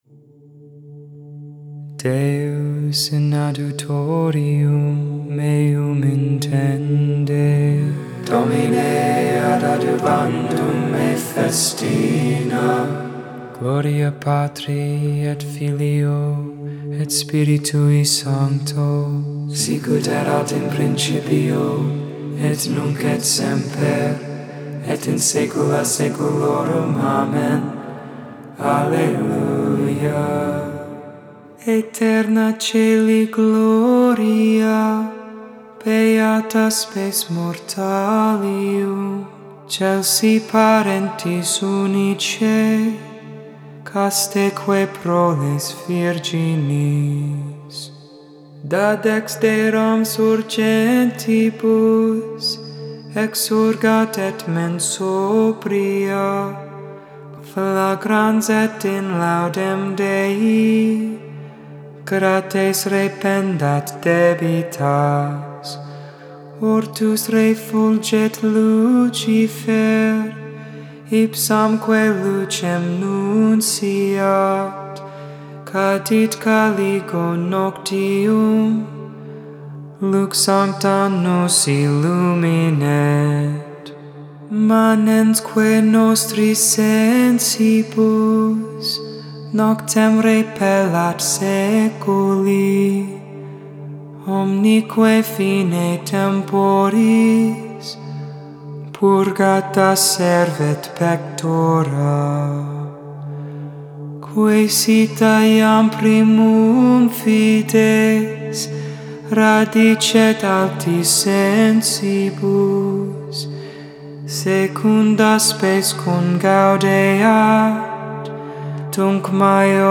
Lauds for the 1st Friday in Ordinary Time.